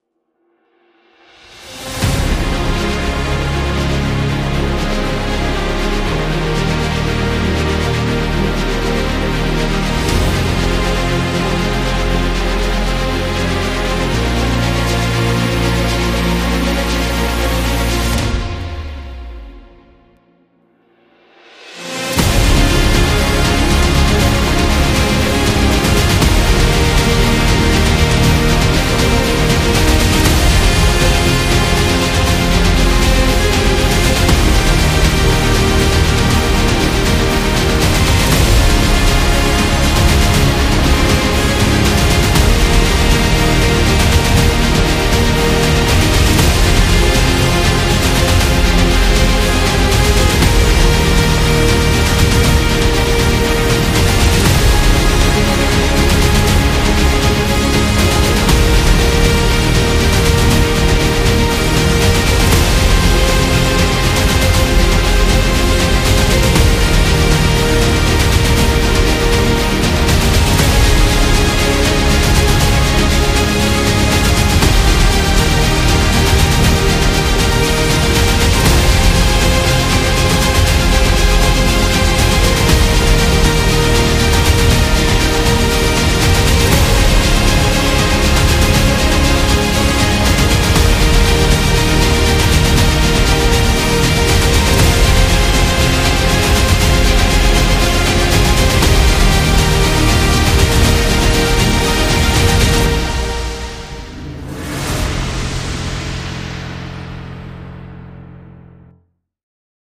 Cinematic